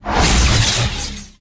New sound effect for ninja phasing.
phasein.ogg